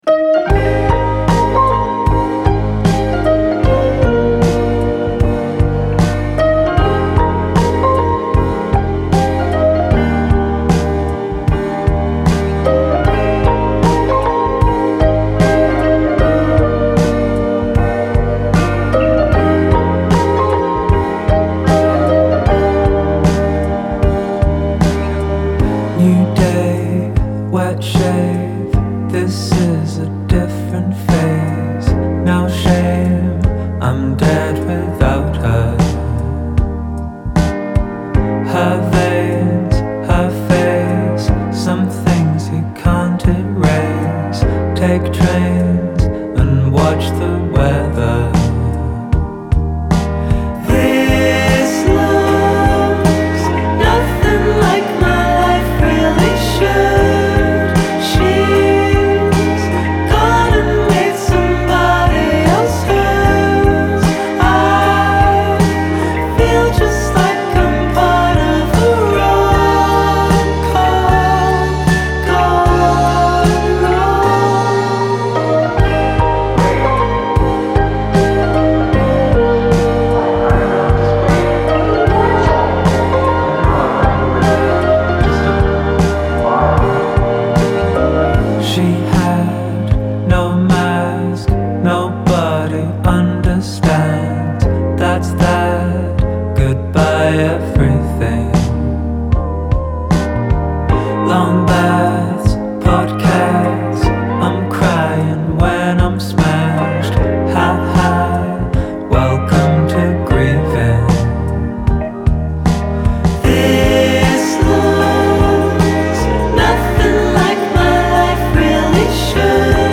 Genre : Alternative, Rock